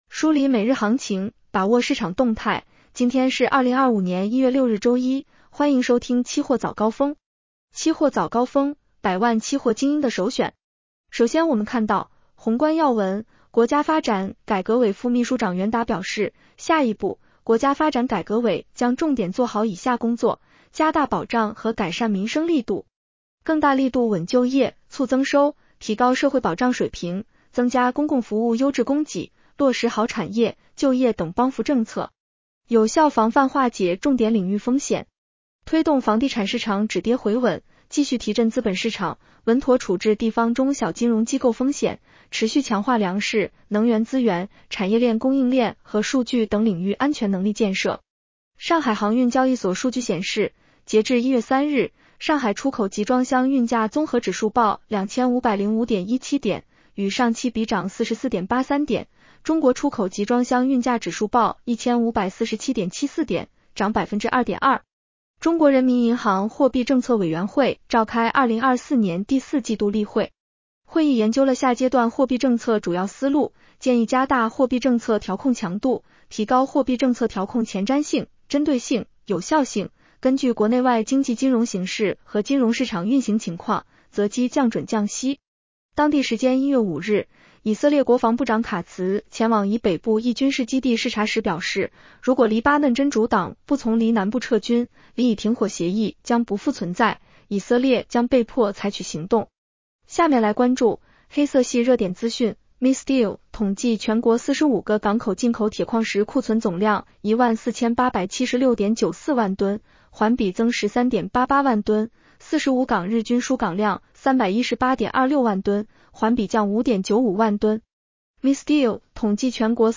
期货早高峰-音频版 女声普通话版 下载mp3 宏观要闻 1.国家发展改革委副秘书长袁达表示，下一步，国家发展改革委将重点做好以下工作：加大保障和改善民生力度。